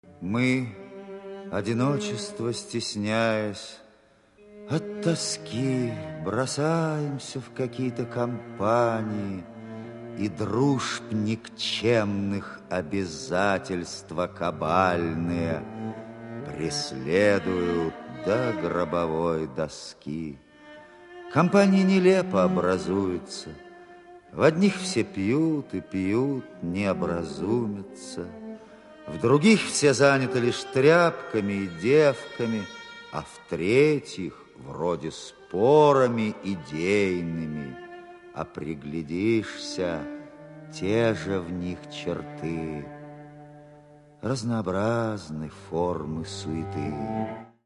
Литературно-музыкальная композиция(РОК-СЮИТА)
По-моему, голос и интонации те же.